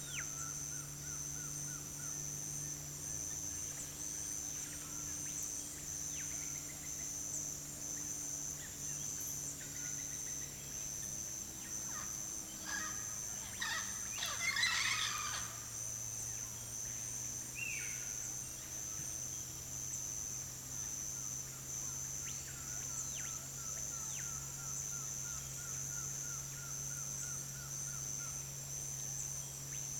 rainforest
bird-voices